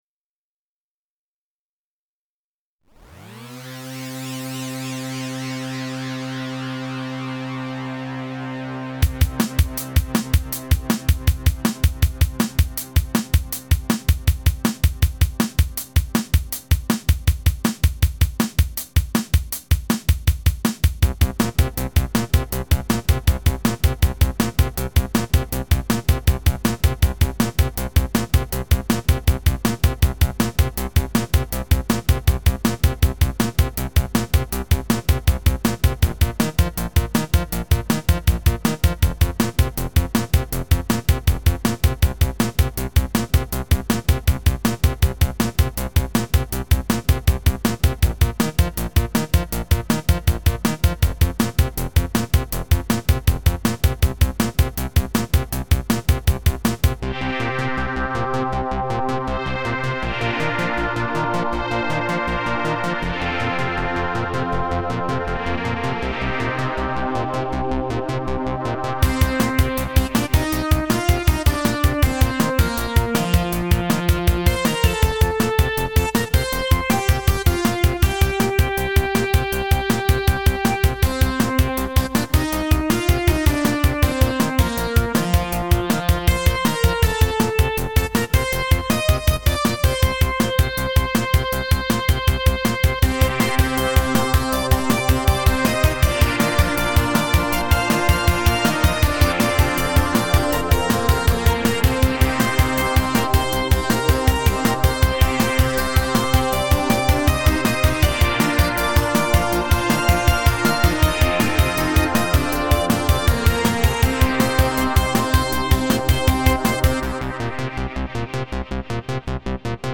J'essaye de bosser sur un morceau dans l'esprit demoparty, est ce que je suis dedans ou pas ?
Yop j'ai �cout� �a super rapidement ce matin et je trouve que c'est un peu trop r�p�titifs sur les accords de fond pour faire vraiment style Amiga.